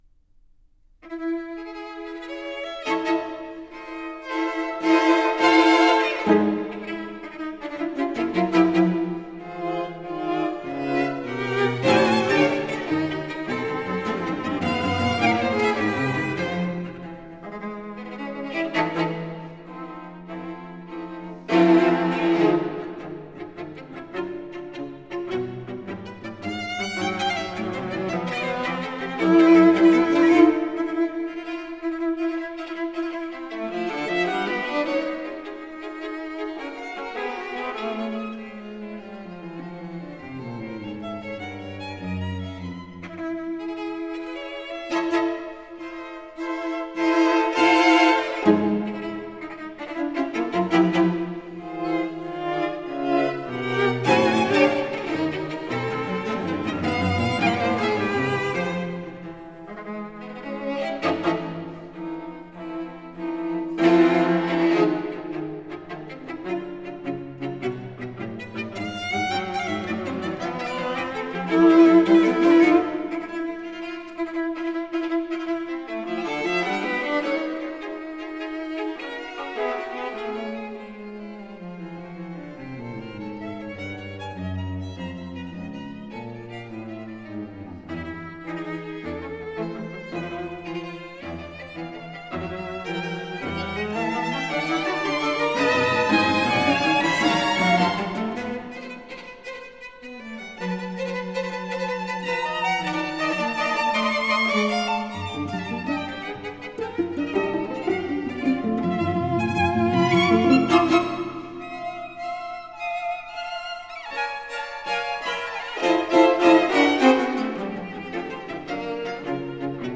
personal and highly charged music